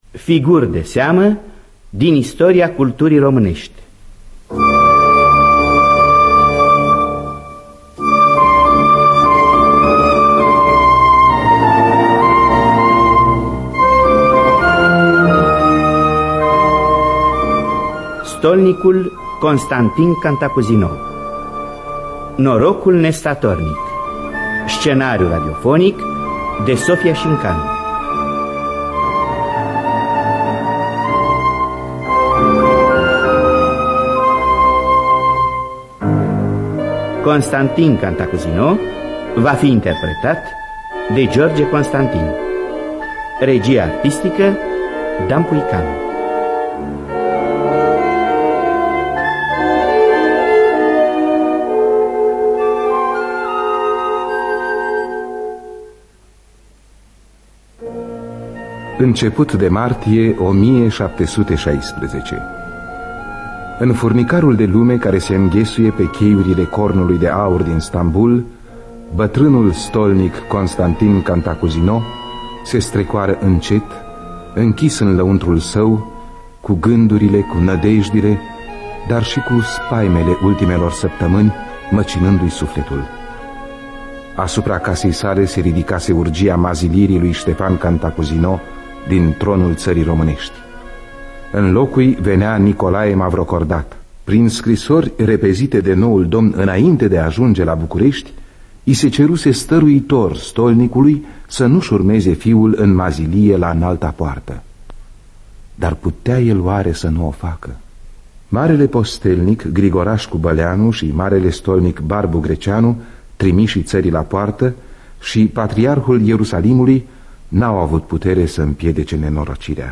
Biografii, memorii: Stolnicul Constantin Cantacuzino. Scenariu radiofonic